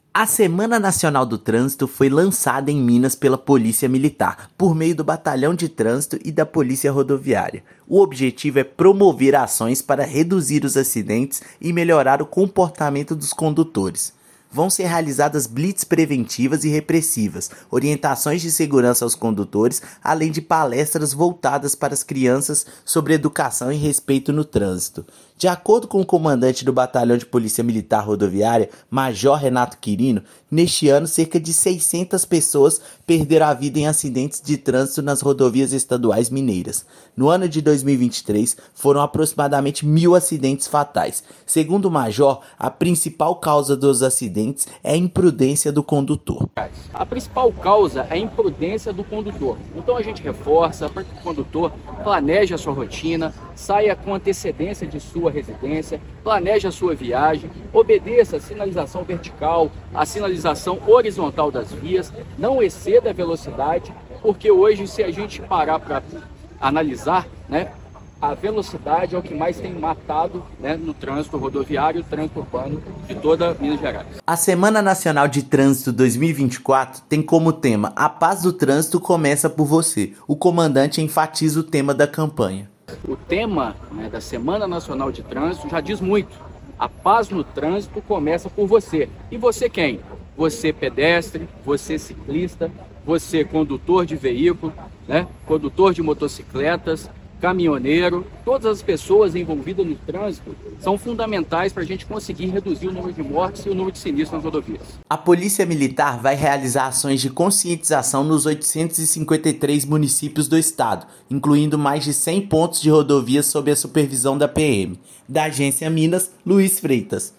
Operações em áreas urbanas e estradas vão contar com apoio dos órgãos estaduais e municipais nos 853 municípios. Ouça matéria de rádio.